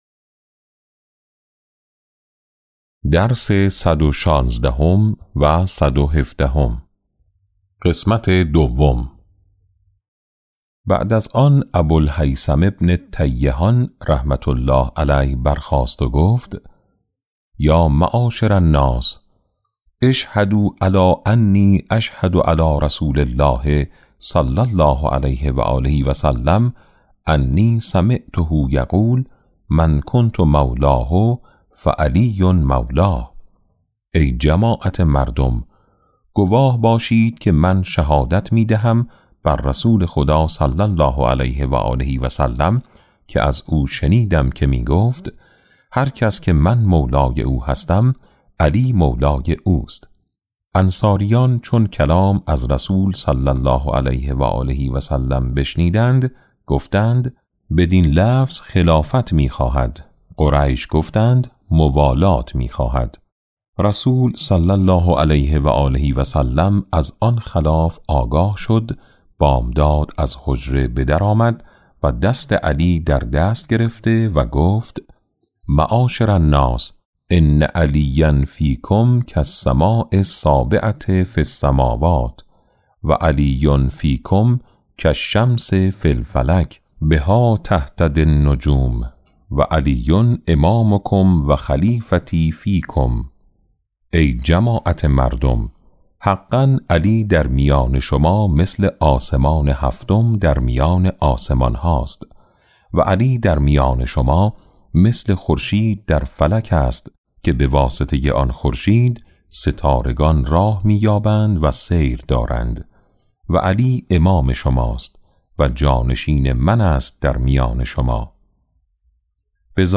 کتاب صوتی امام شناسی ج۸ - جلسه10